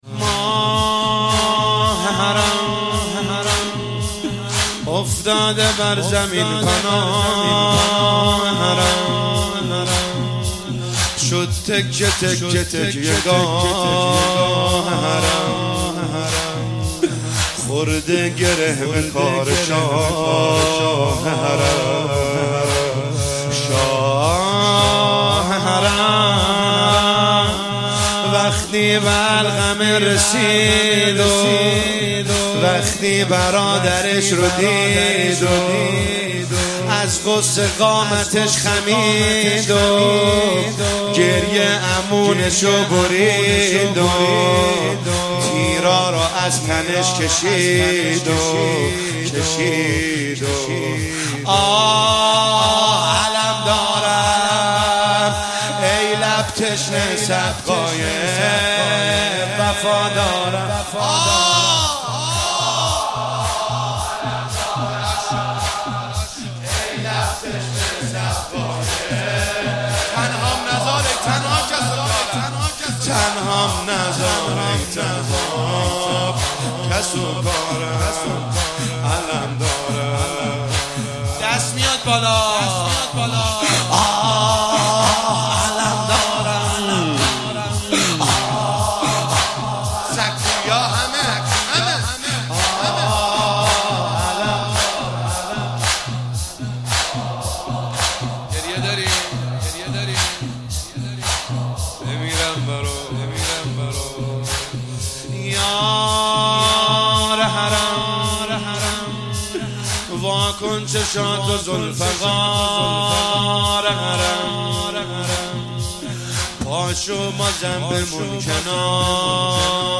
شب تاسوعا محرم1401 - زمینه - ماه حرم، افتاده